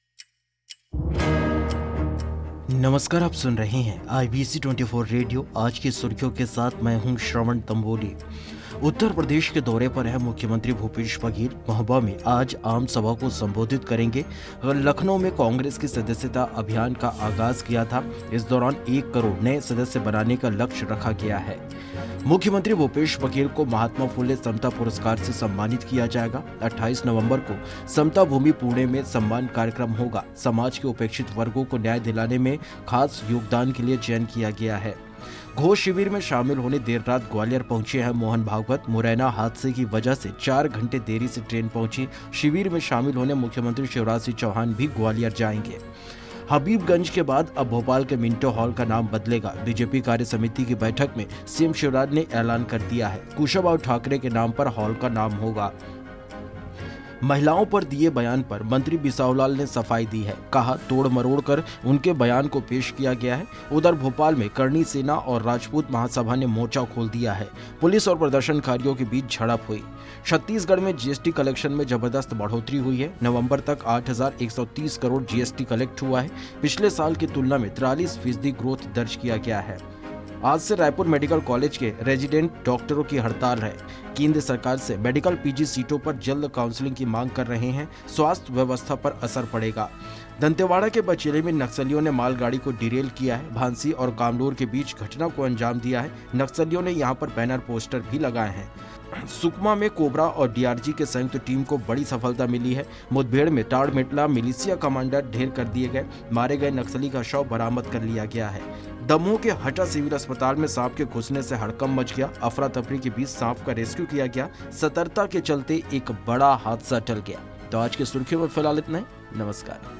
Podcast hindi news 2021